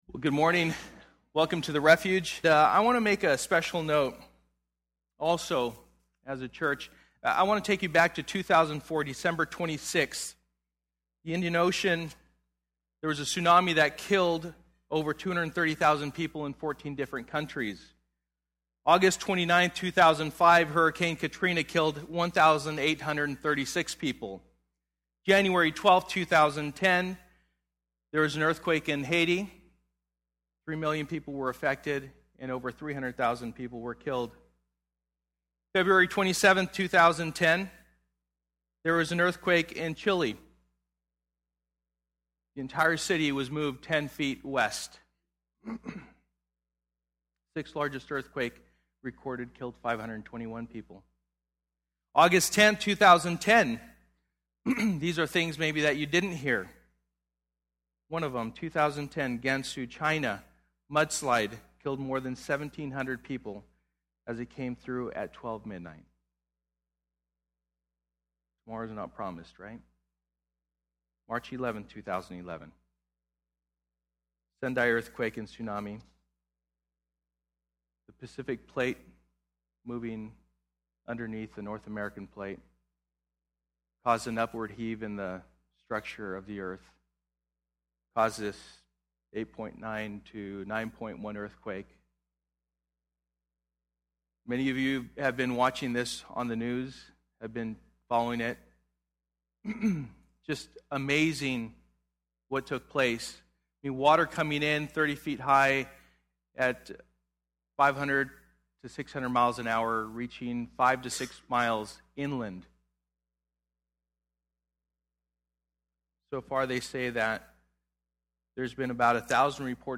Sold Out Passage: 1 Corinthians 6:12-20 Service: Sunday Morning %todo_render% « Dedication Service Lord Show Me Your Power!